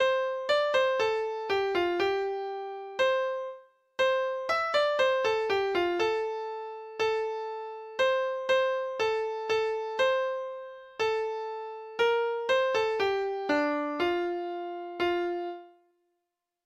data-generert lydfil